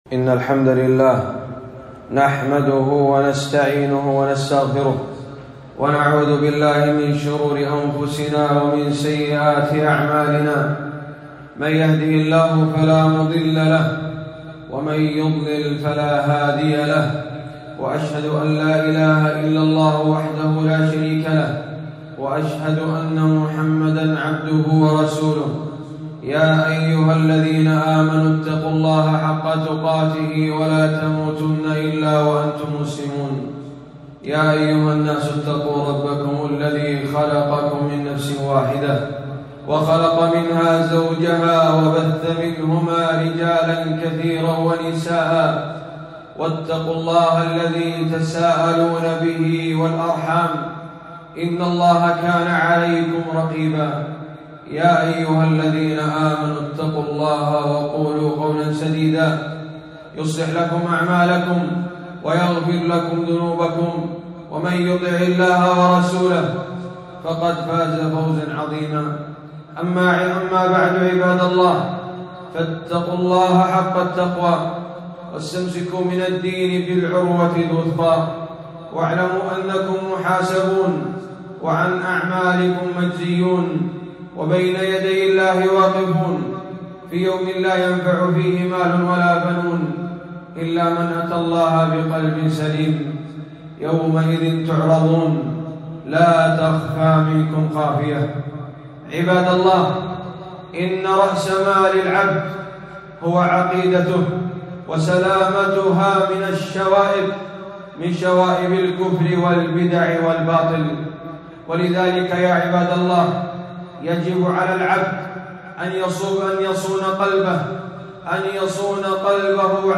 خطبة - حفظ القلوب من شبهات المبطلين